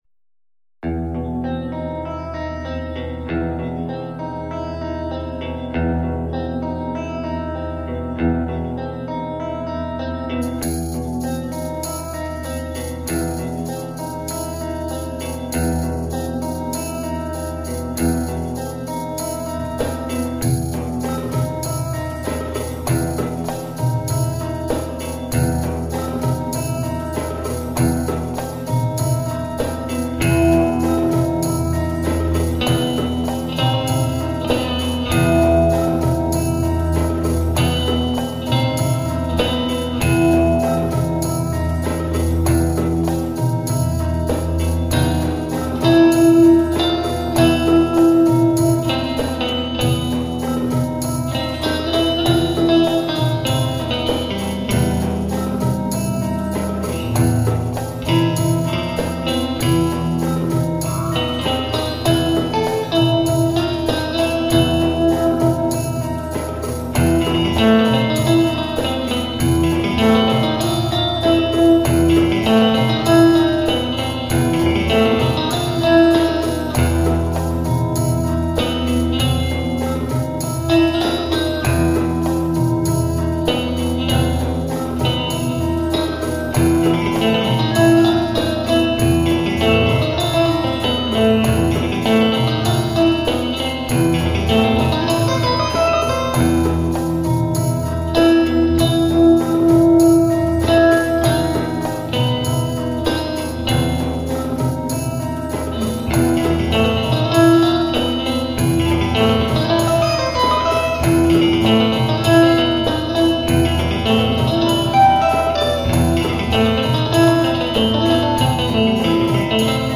set the delay time to exactly 9 msec, the feedback to a high value, adjust the wet/dry and connect a guitar to its input. the sound will be a resonating A sitar-like one
it's an experiment.
the infinty was used for initial arpeggio (a loop), but with low wet signal.
the solo guitar is where the FX is more pronunced.
nice result. the delay becomes like a metallic resonant body. this has a very "soundtrack" quality for me, I could see the opening credits to an exotic thriller emerging before me.